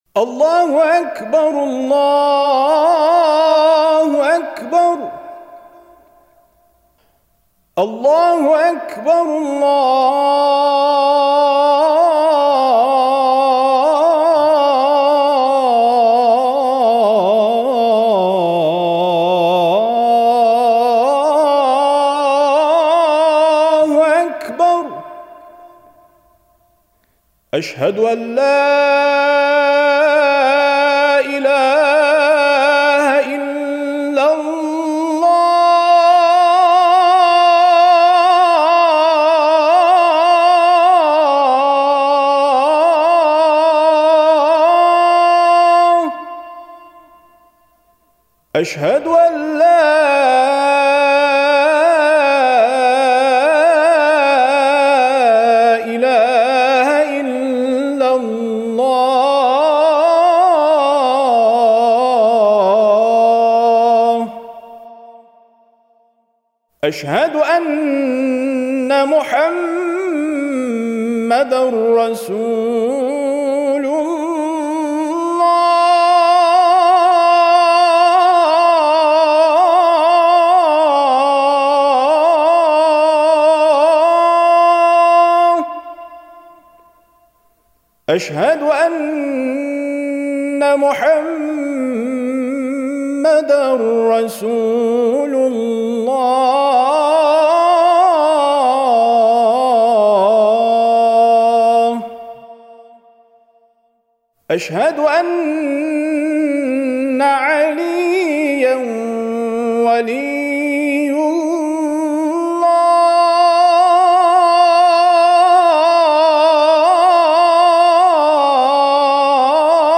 فایل صوتی اذان